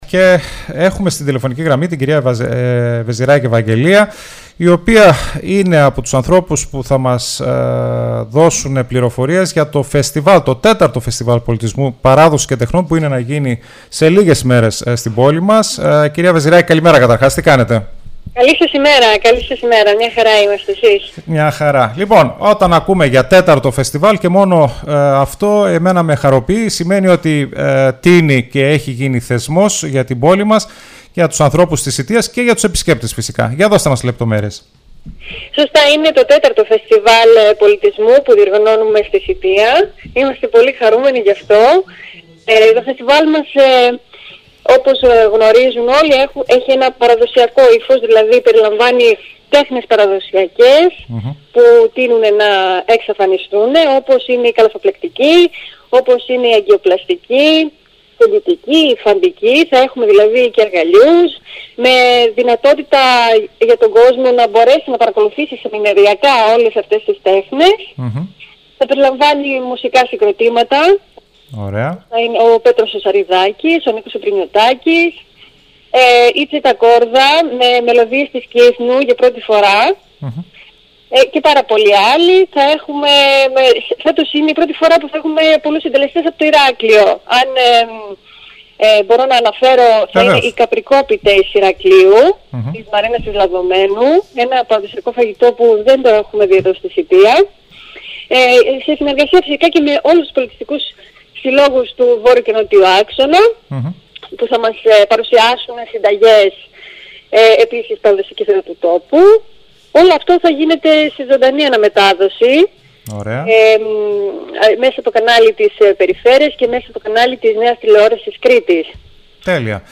ΣΥΝΕΝΤΕΥΞΗ